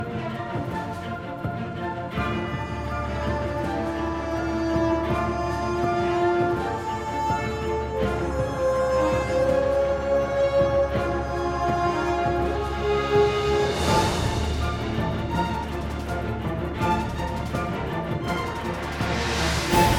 Musique multipistes.
Pistes : 10 (dont des cœurs)